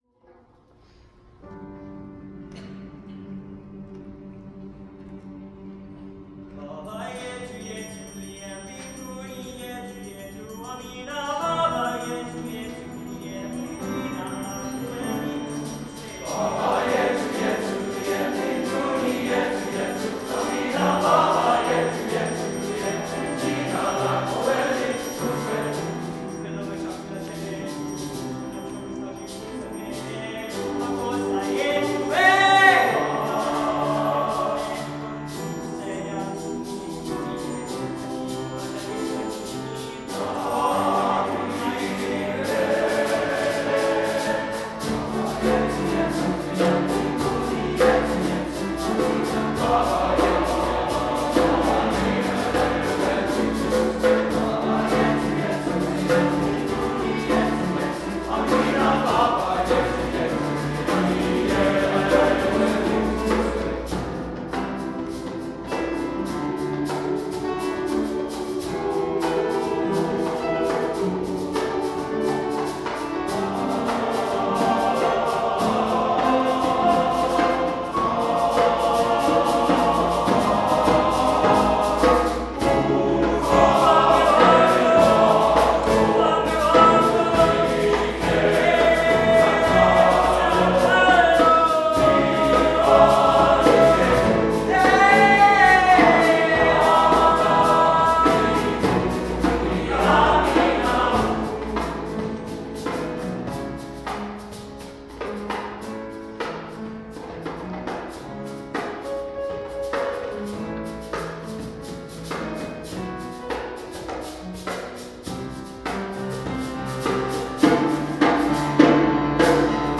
Statesmen: Baba Yetu – Christopher Tin